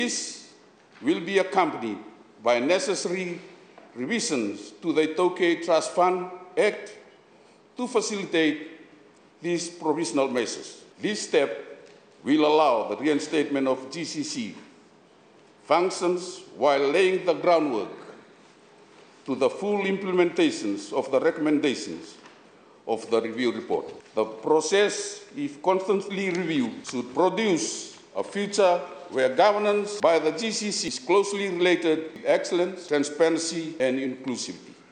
Minister of iTaukei Affairs Ifereimi Vasu provided the progress update on the GCC in parliament today.